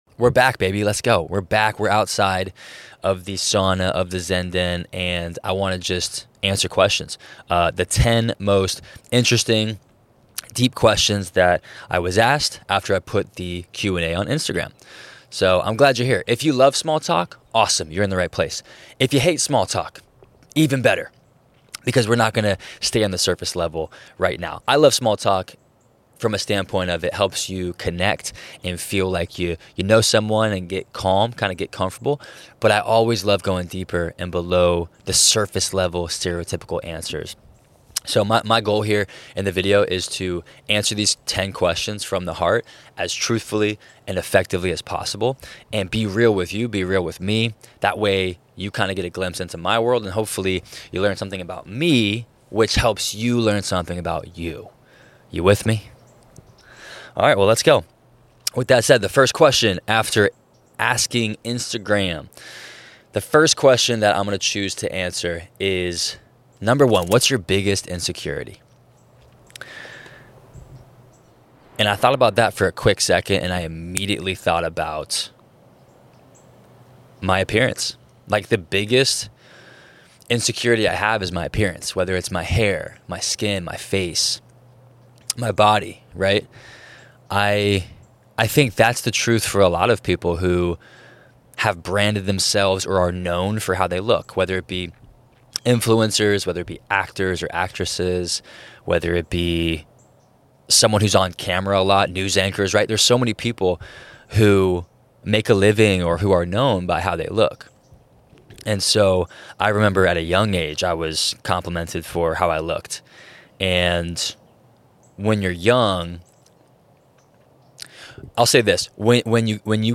This episode is casual and unfiltered… just raw truth.